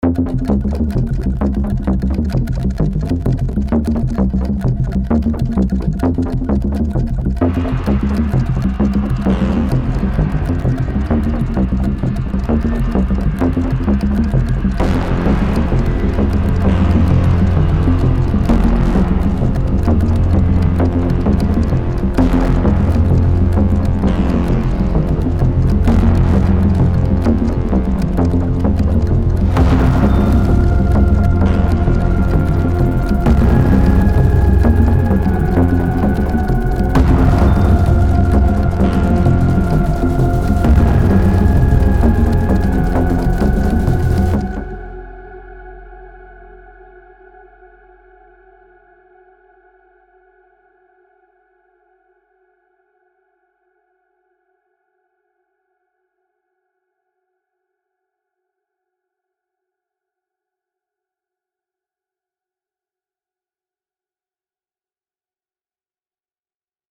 Саундтрек с триллерным настроением